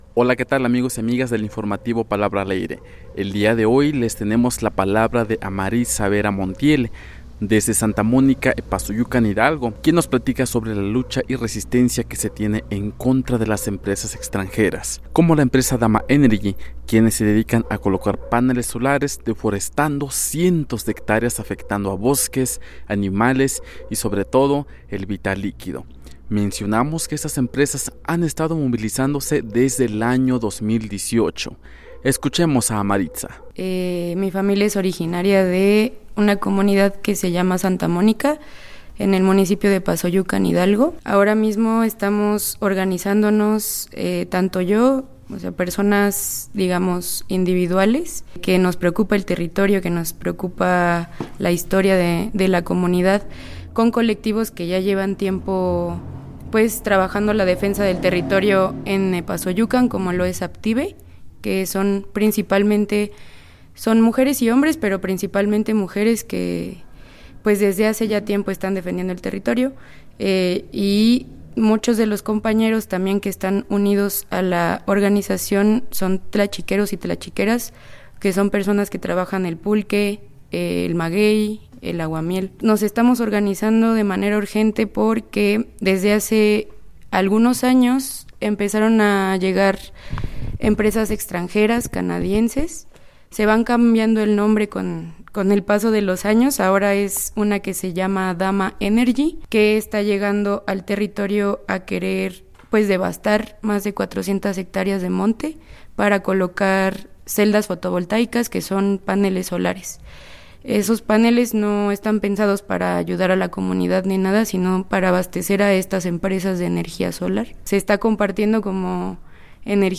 El reporte